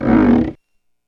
Index of /90_sSampleCDs/E-MU Producer Series Vol. 3 – Hollywood Sound Effects/Miscellaneous/Rubber Squeegees
SQUEEGEE 4.wav